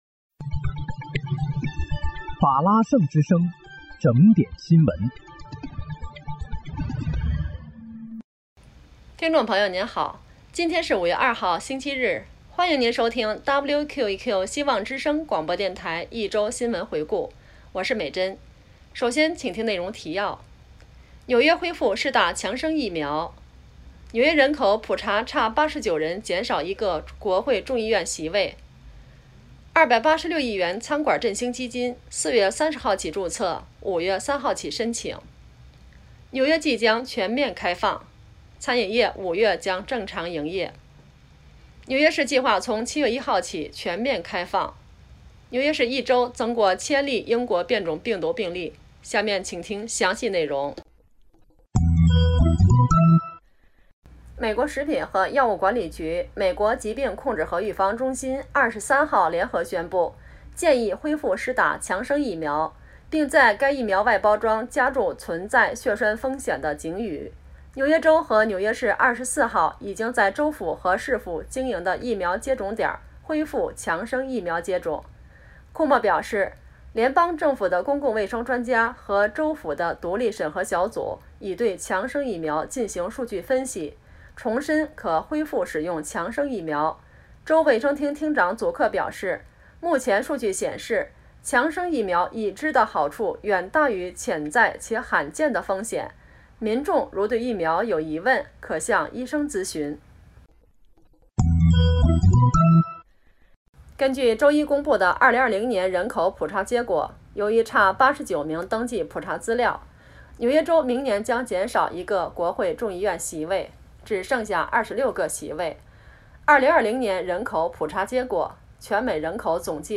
5月2日（星期日）一周新闻回顾